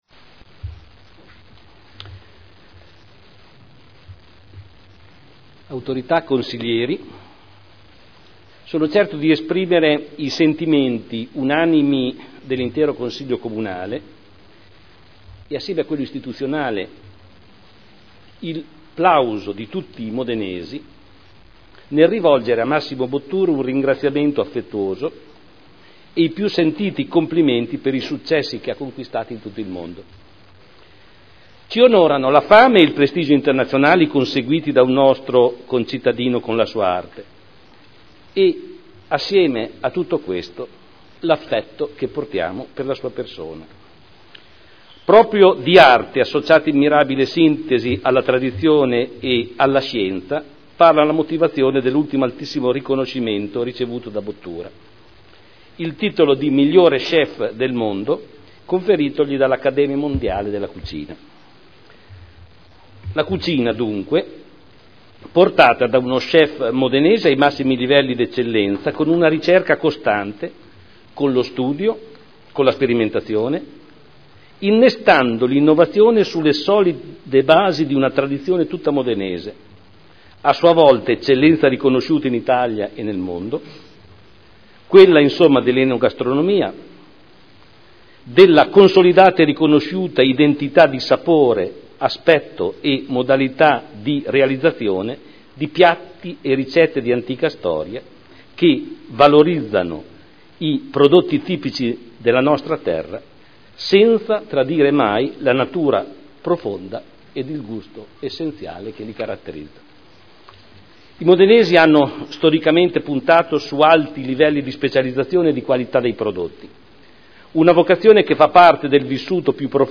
Seduta del 04/04/2011 interviene alla Cerimonia di premiazione a Massimo Bottura.